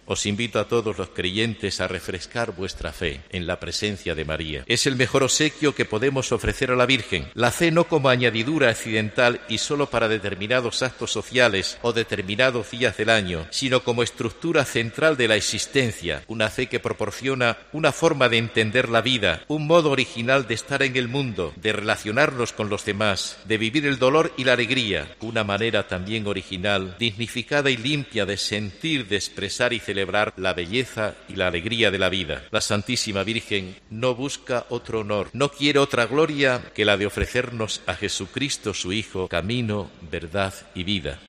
Ciriaco Benavente, obispo de Albacete
catedral de Albacete en el dia de la patrona
Pueden escucharse tres fragmentos de la homilia en la Eucaristía celebrada con motiivo de la festividad de la Virgen de los Llanos, patrona de Albacete, a la que han asistido cientos de albaceteños, así como representantes de todas las instituciones civiles y militares de Albacete.